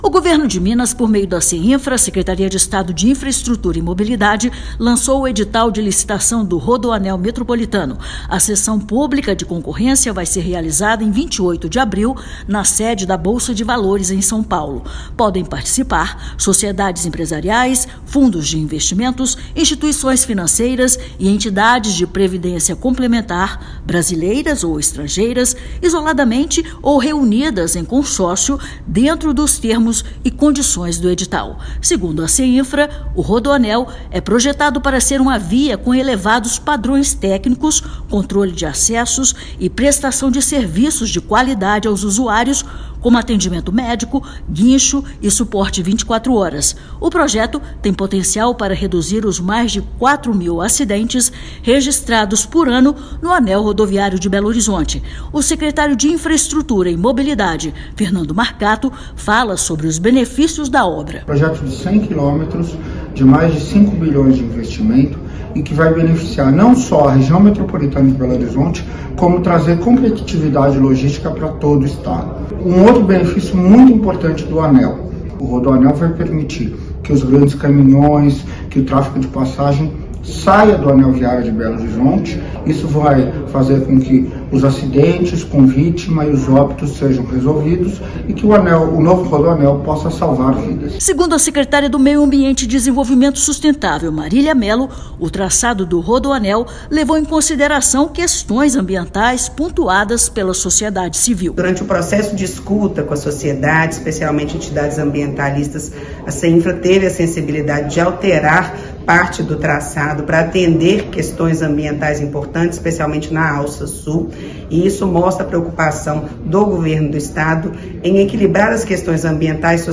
O Governo de Minas, por meio da Secretaria de Estado de Infraestrutura e Mobilidade (Seinfra), lançou o edital de licitação do Rodoanel Metropolitano. A sessão pública de concorrência vai ser realizada em 28/4, na sede da Bolsa de Valores, em São Paulo. Ouça matéria de rádio.